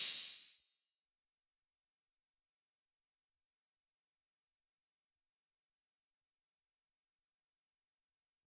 OpenHat (Glow).wav